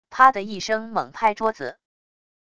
啪的一声猛拍桌子wav音频